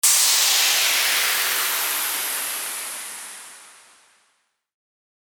FX-1883-WHOOSH
FX-1883-WHOOSH.mp3